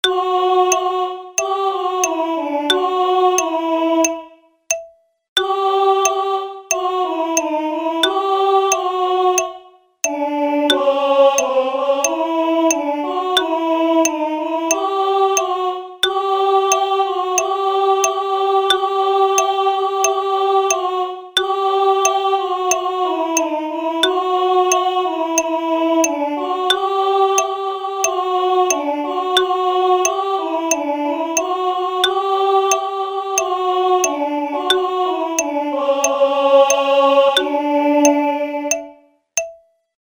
Alt z metronomom
Z_Bogom_Marija_pravi_ALT_..mp3